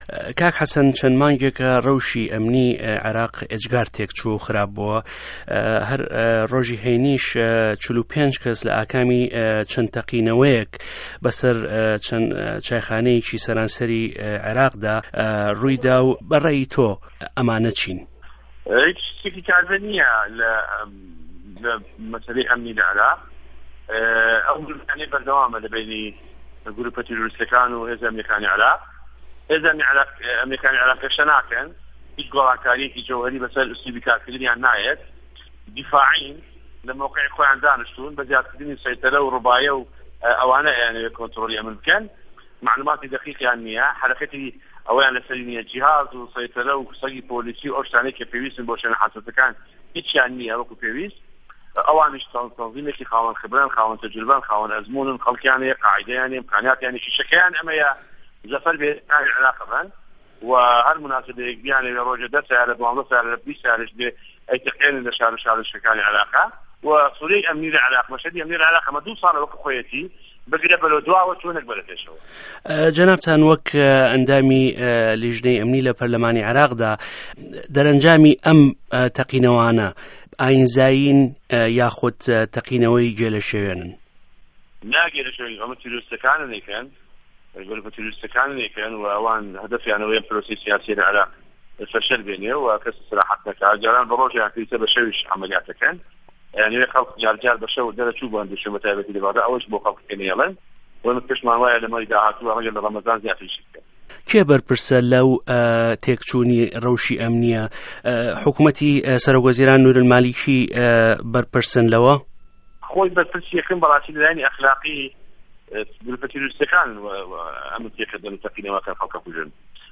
وتووێژی حه‌سه‌ن جیهاد